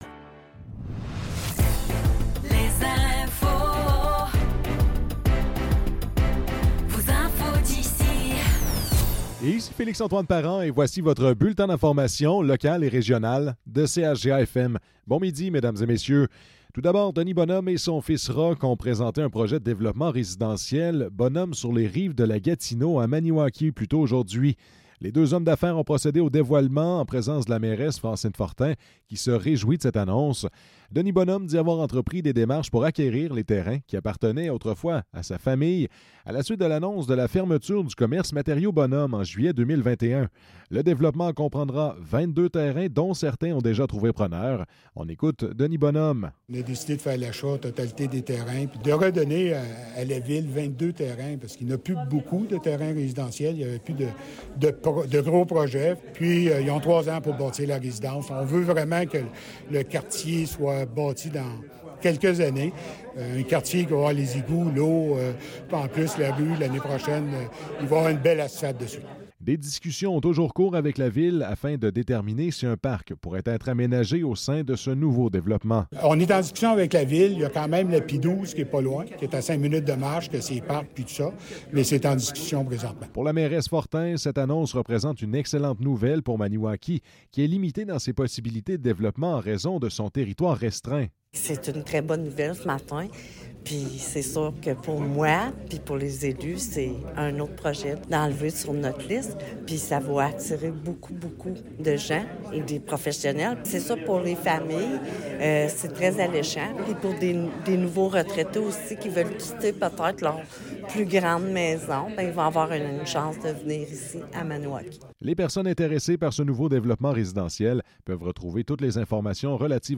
Nouvelles locales - 18 novembre 2024 - 12 h